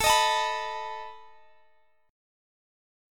Listen to Asus2b5 strummed